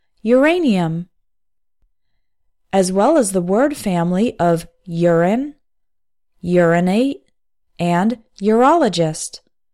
English words starting with U – “yer” sound